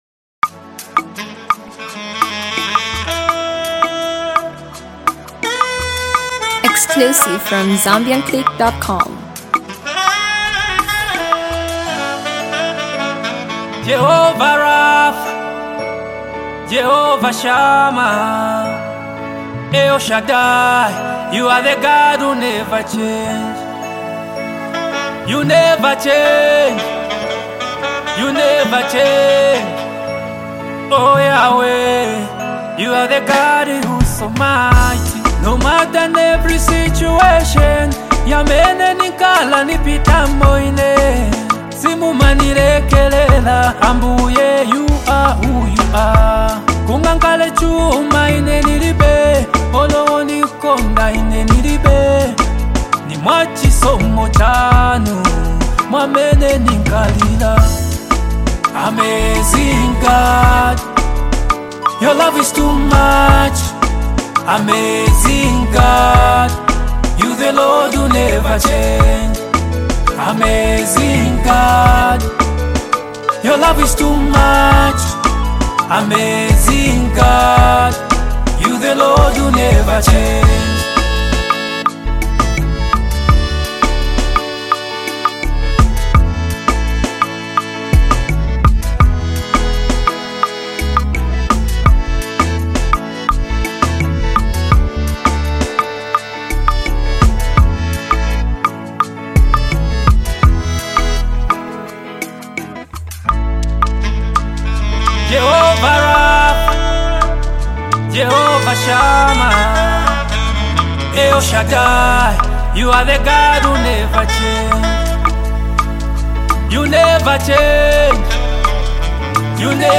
Zambian gospel singer